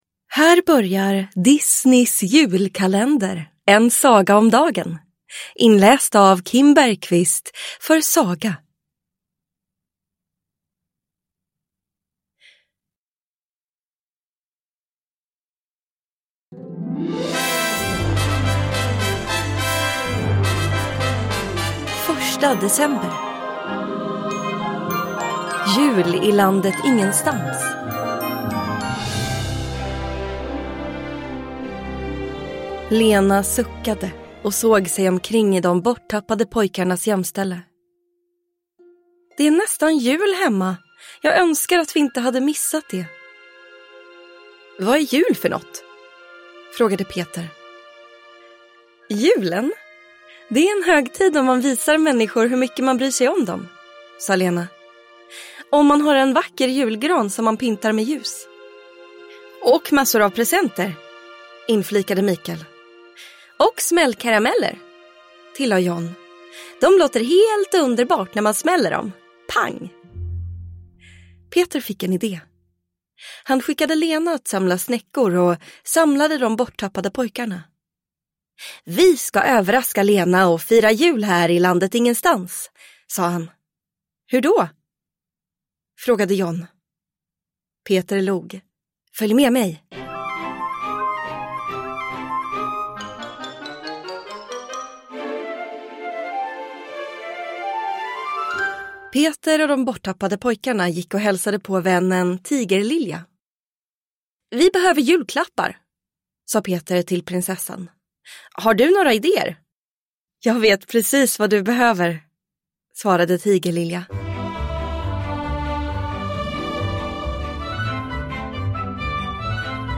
Ljudbok
Nedräkningen till jul har börjat - nu med fantastisk musik och ljudeffekter!